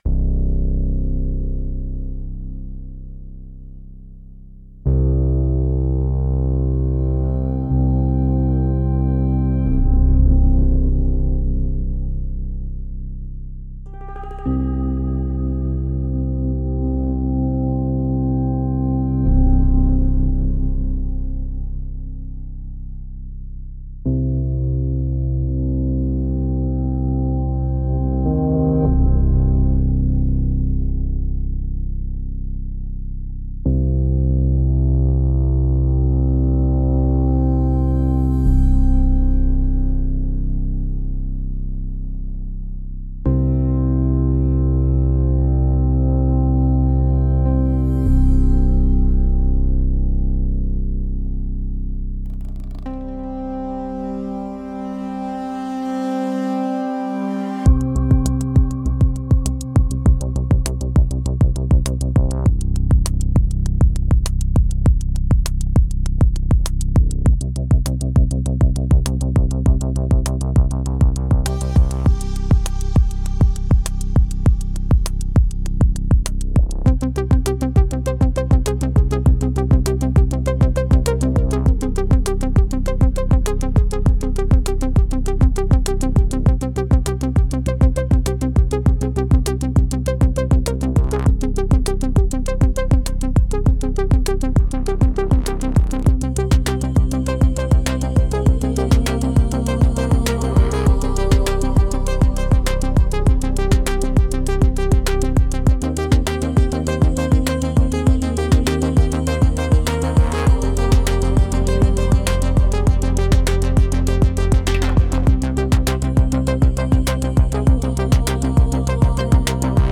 Ai instrumental